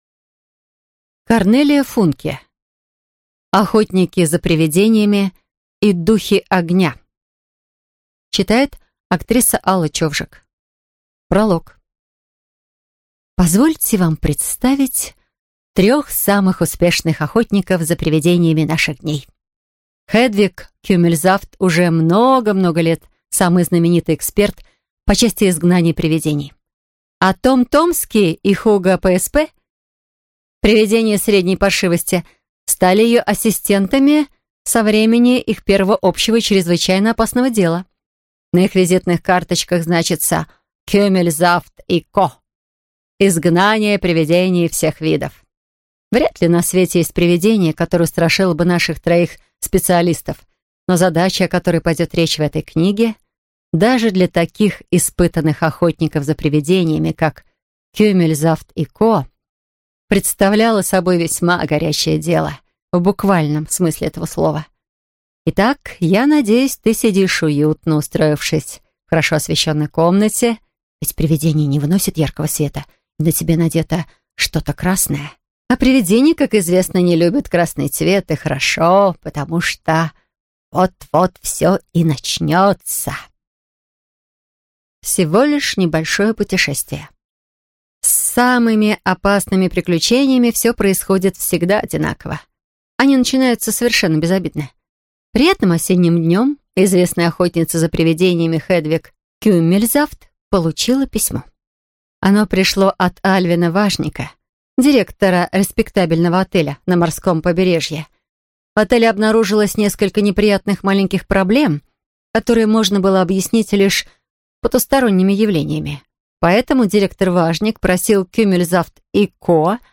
Аудиокнига Охотники за привидениями и духи огня | Библиотека аудиокниг